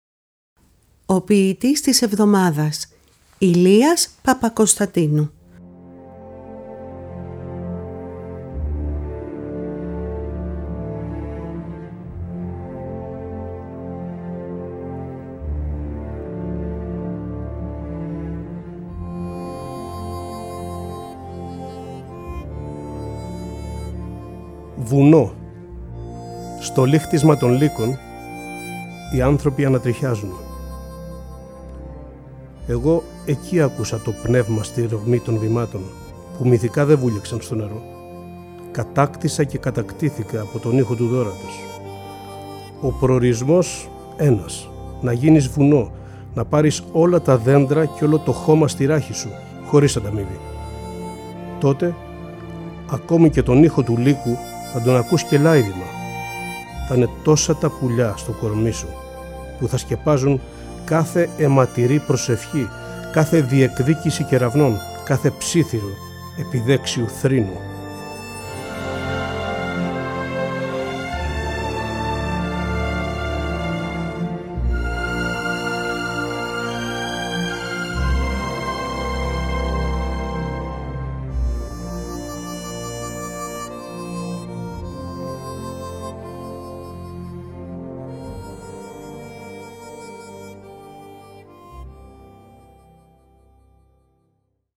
Κάθε εβδομάδα είναι αφιερωμένη σ’ έναν σύγχρονο Έλληνα ποιητή ή ποιήτρια, ενώ δεν απουσιάζουν οι ποιητές της Διασποράς. Οι ίδιοι οι ποιητές και οι ποιήτριες επιμελούνται τις ραδιοφωνικές ερμηνείες. Παράλληλα τα ποιήματα «ντύνονται» με πρωτότυπη μουσική, που συνθέτουν και παίζουν στο στούντιο της Ελληνικής Ραδιοφωνίας οι μουσικοί της Ορχήστρας της ΕΡΤ, καθώς και με μουσικά κομμάτια αγαπημένων δημιουργών.